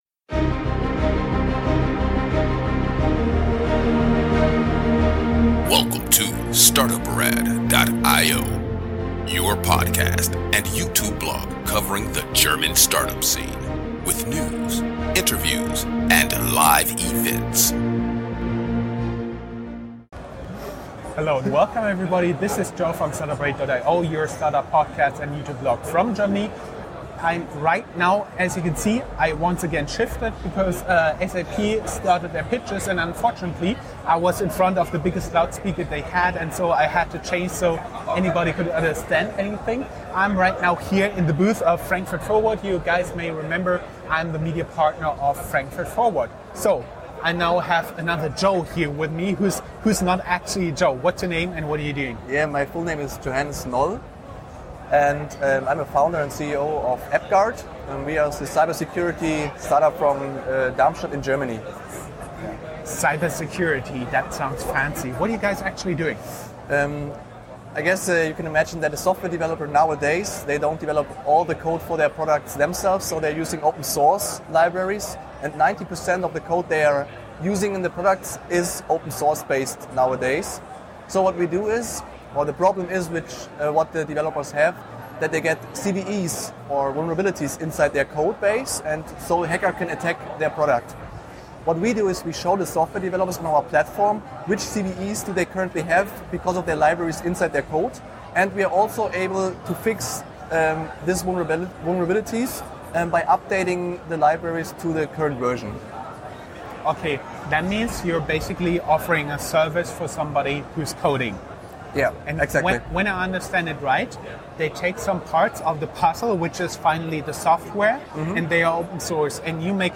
You are listening to the audio track of a YouTube interview.